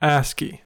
ASS-kee),[3]: 6  an acronym for American Standard Code for Information Interchange, is a character encoding standard for electronic communication.
En-us-ASCII.ogg.mp3